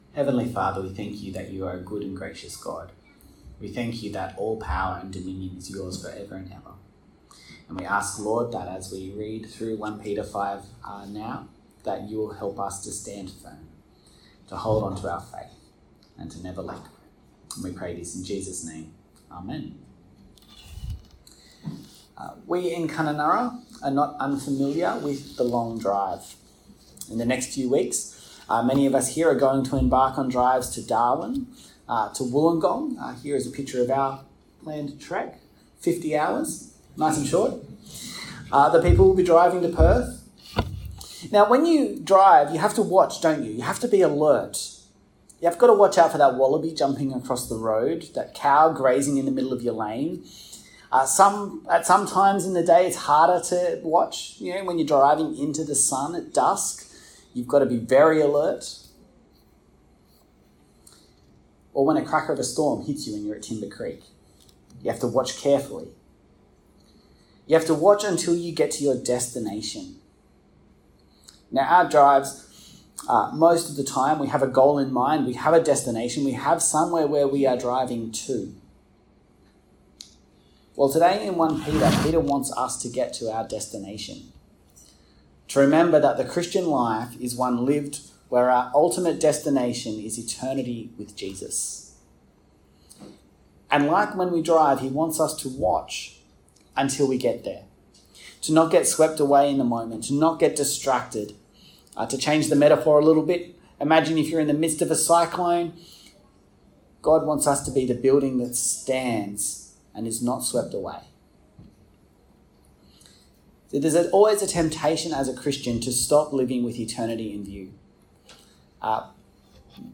Sermon Series - St. James Anglican Church Kununurra